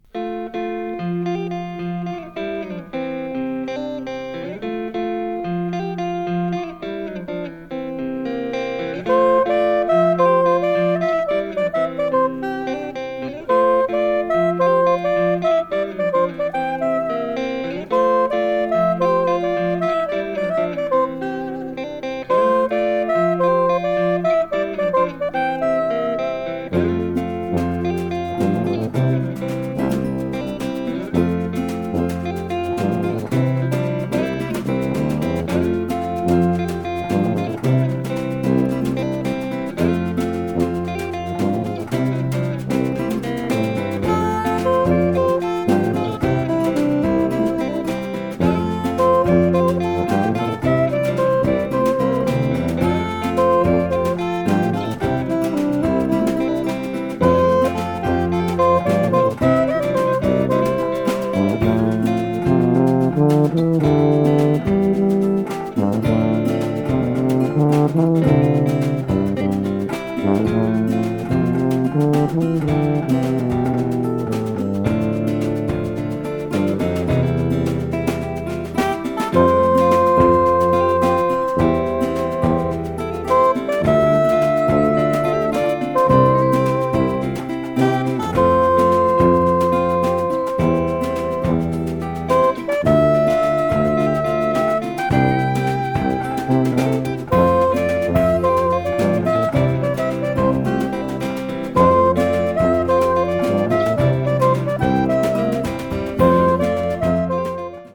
持ち前のジャズとフォルクローレの感覚が益々ポップに花開いた傑作！